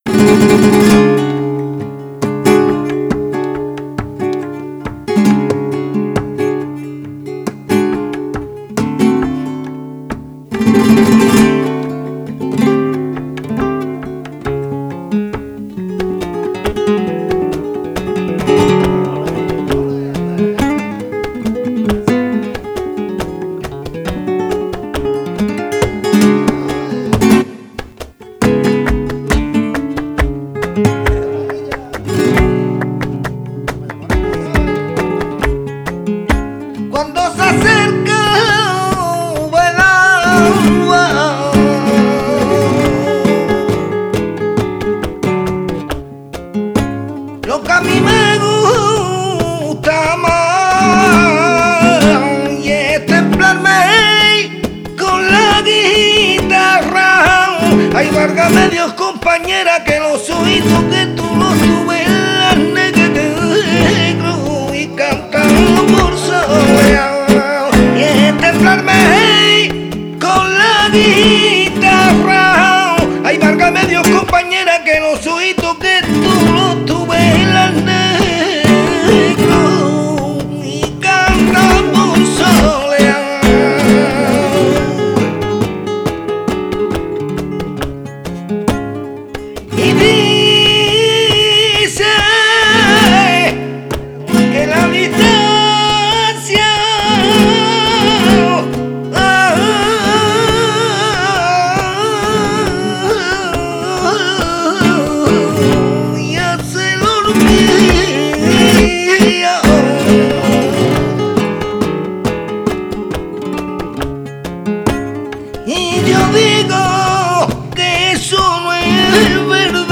bulerías por soleá
chant
guitare